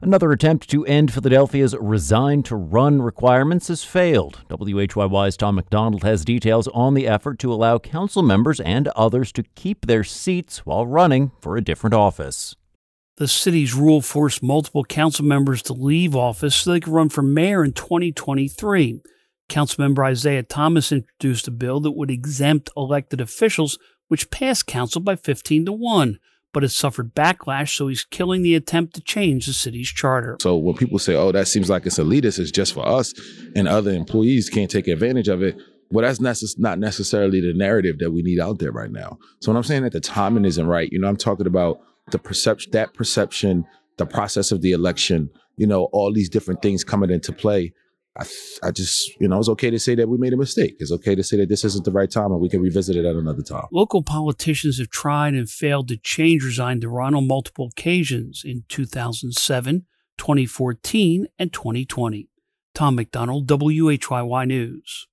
A gathering in north Wilmington marks the start of Hanukkah, the Festival of Lights.